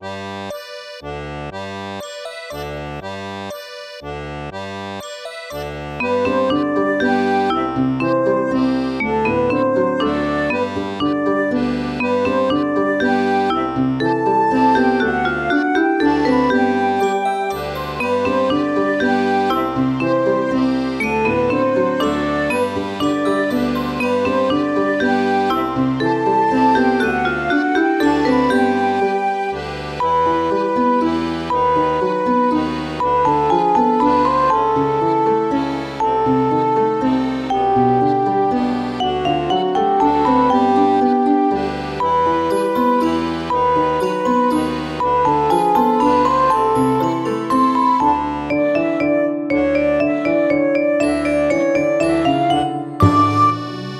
【イメージ】雪の町 など